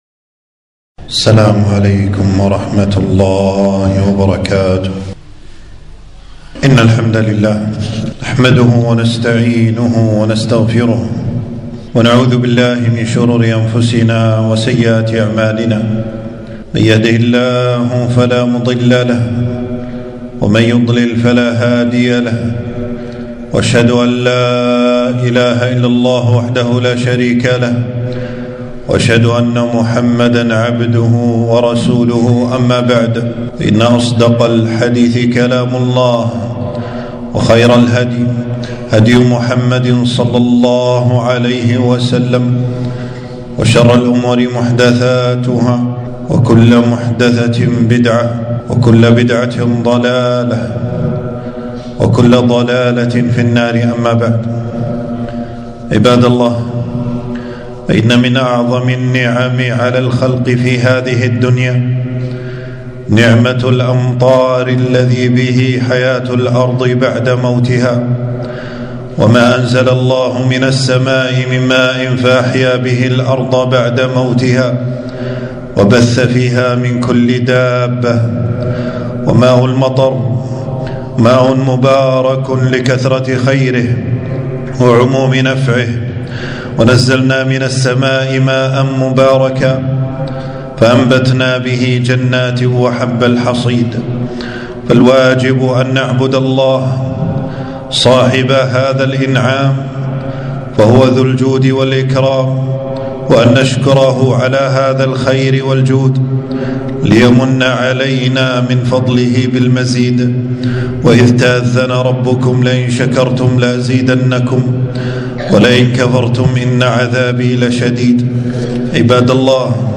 خطبة - نعمة الأمطار والسنن الوارة فيه